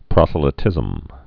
(prŏsə-lĭ-tĭzəm, -lī-)